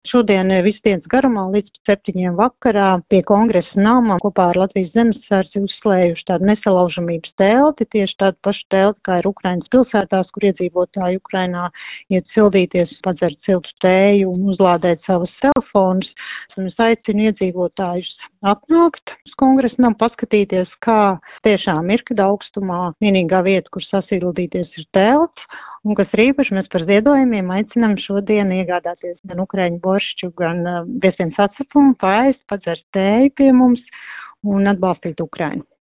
Tāpēc akcijā “Siltums Ukrainai no Latvijas” aicina ziedot Ukrainas enerģētikas atbalstam, to intervijā Skonto mediju grupai (SMG) apliecināja